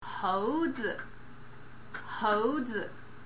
描述：我和我的foley班的其他成员为《夺宝奇兵》第四部电影的丛林追车部分制作的foley SFX。
标签： 爪子 运行
声道立体声